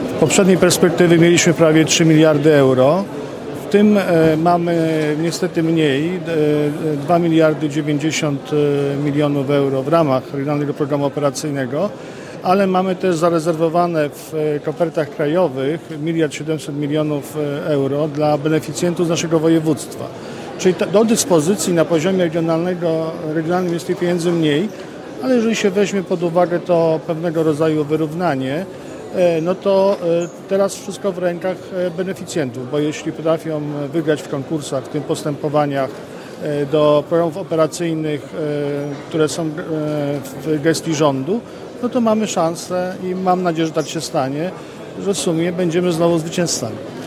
Adam Struzik - Marszałek Województwa Mazowieckiego